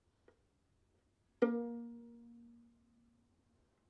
小提琴：单音 拨弦 G4 G5 " 小提琴 A4 拨弦 非颤音
描述：这是一个小提琴在第四个八度演奏A音的乐器样本。这个音符的攻击和延音是拨弦和非颤音。所用的调谐频率（音乐会音高）是442，动态意图是夹竹桃。
标签： 多重采样 放大H2N 非颤音 拨弦 mezzoforte A-4 串仪器 弦鸣乐器 小提琴
声道立体声